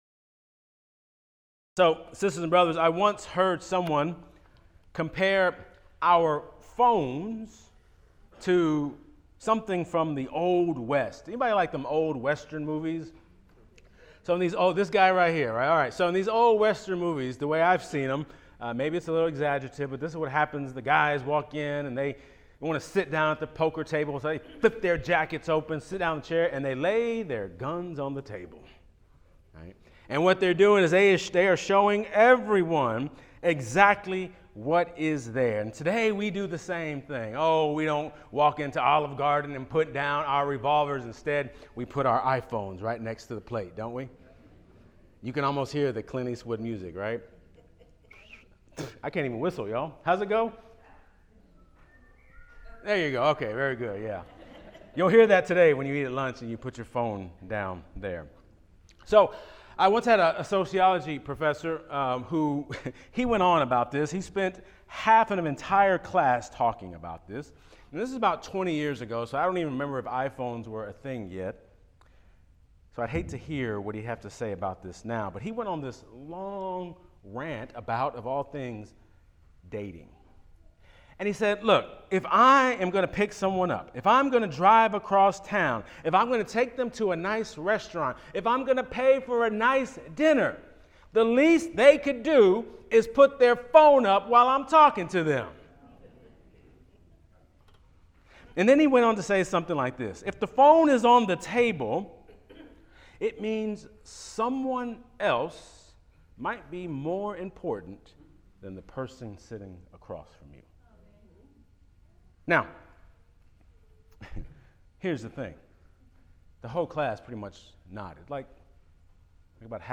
Sermons | Kelsey Memorial UMC